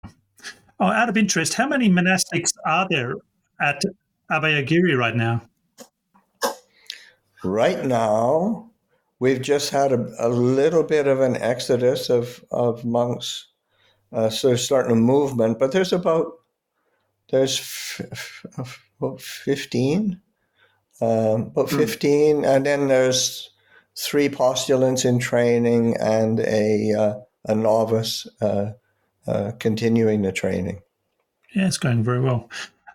Online interview